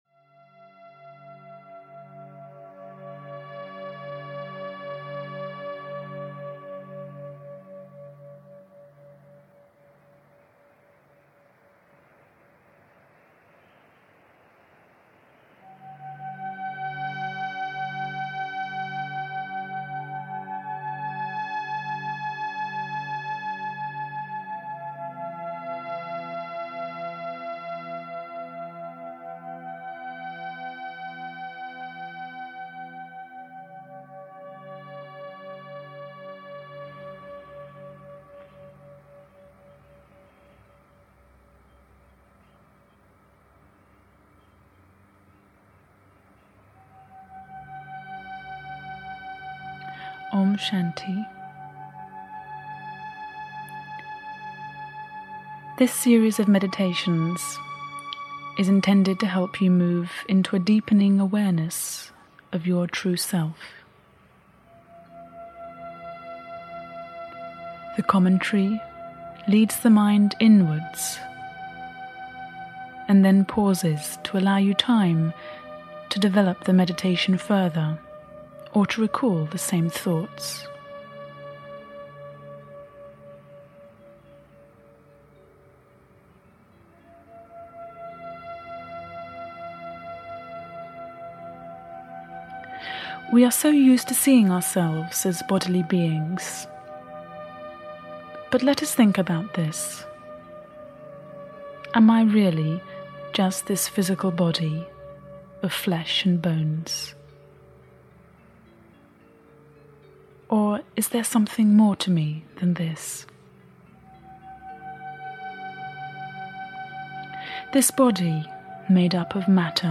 Progressive Meditations (EN) audiokniha
Ukázka z knihy